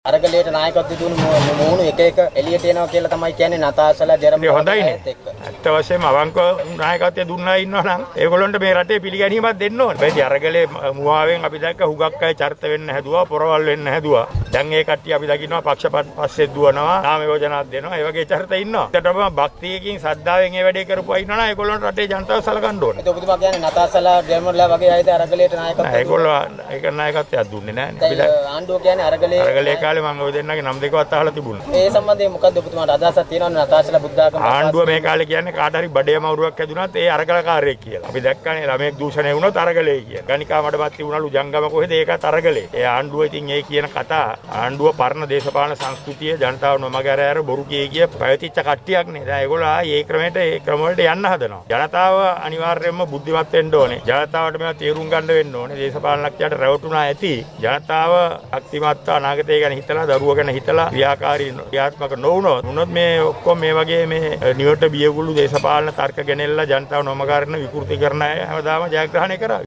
-සරත් ෆොන්සේකාගෙන් ප්‍රකාශයක්
මන්ත්‍රීවරයා මේ බව ප්‍රකාශ කලේ ඊයේ මාධ්‍ය වෙත අදහස් දක්වමින් .